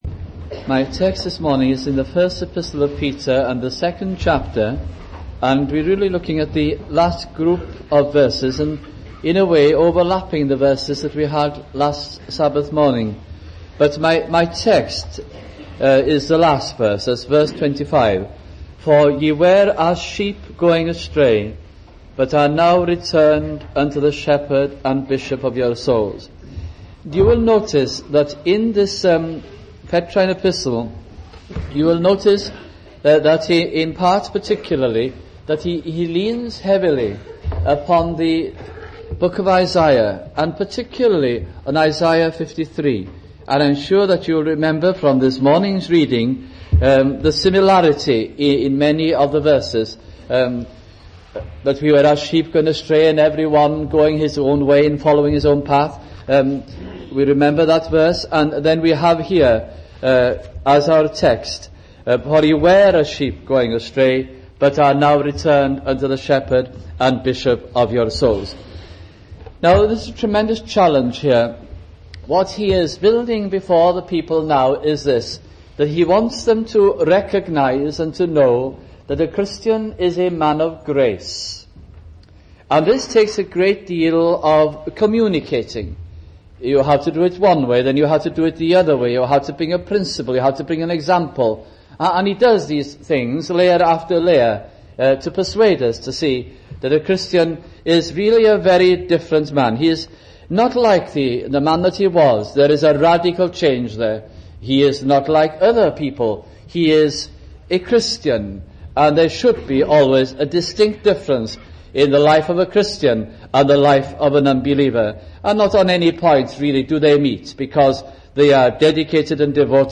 » First Epistle of Peter Series 1982 - 1983 » sunday morning messages from this gracious epistle